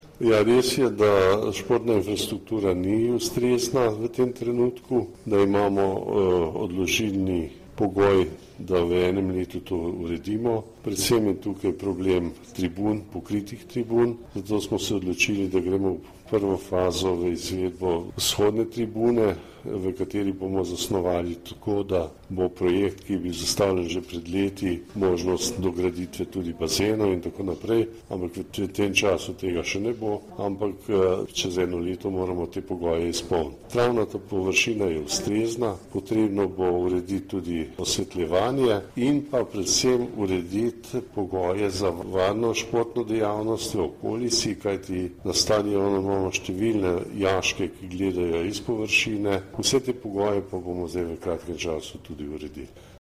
Župan Alojzij Muhič o potrebnih delih na športni infrastrukturi